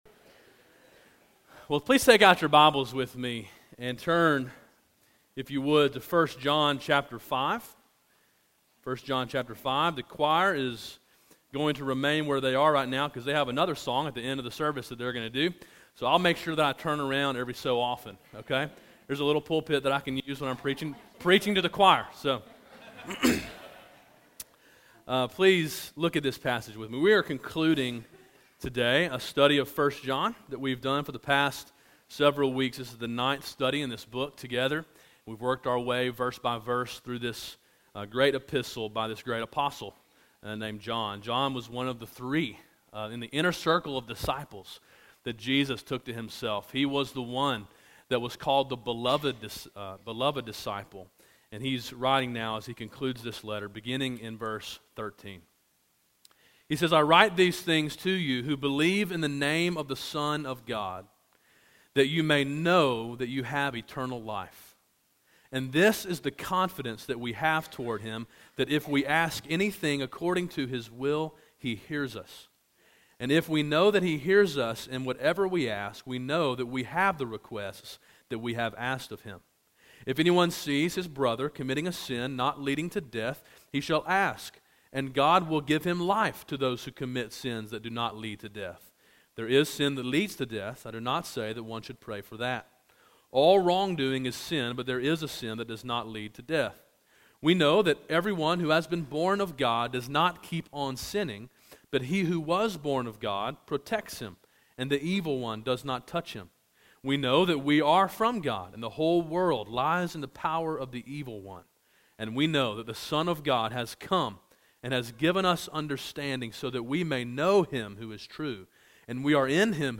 A sermon in a series titled Signs of Salvation: a Study of 1 John.